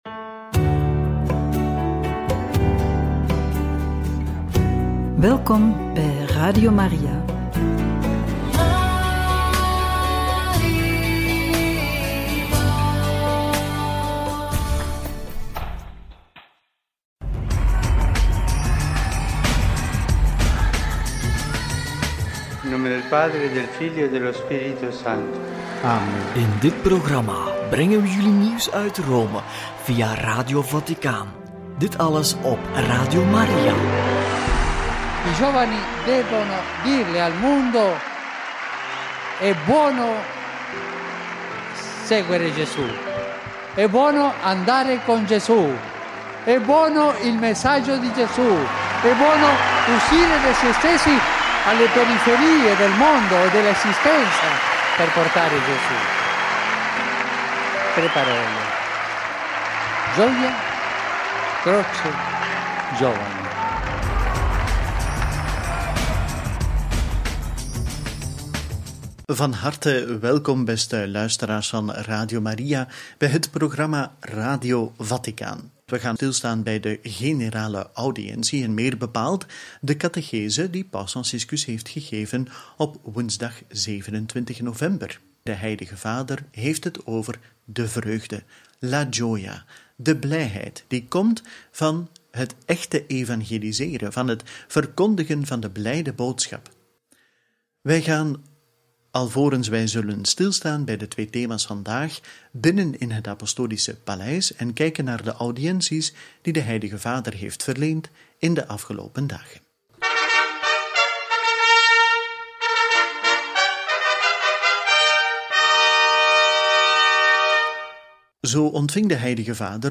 Toespraak tot de Pauselijke Theologische Commissie – Generale audiëntie over de vreugde – Paus Franciscus over Radio Maria in 2015 – Radio Maria